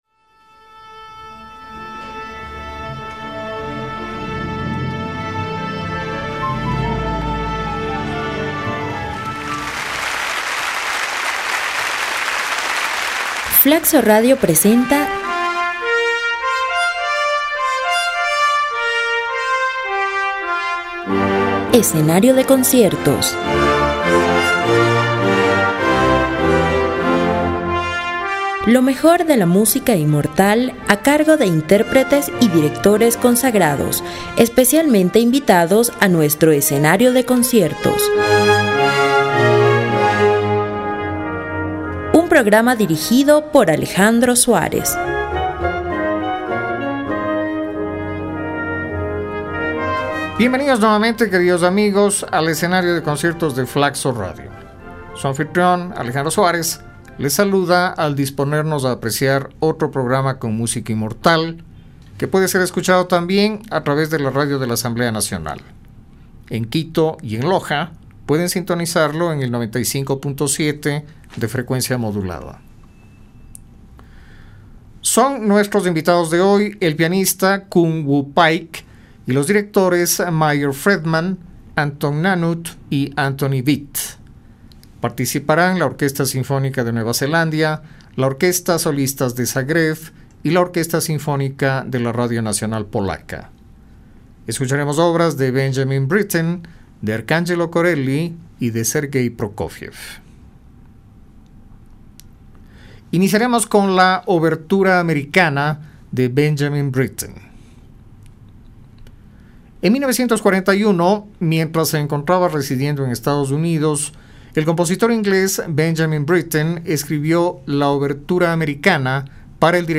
De este músico escucharemos esta semana dos obras: la Obertura de la ópera La finta giardiniera y el Concierto para piano N. 21.
De este gran compositor ruso escucharemos el Concierto para piano N.3. En el día dedicado a la música infrecuente podremos escuchar una selección de muy interesantes composiciones de músicos chinos.
El Escenario de Conciertos cerrará esta semana con la monumental sexta sinfonía de Anton Bruckner.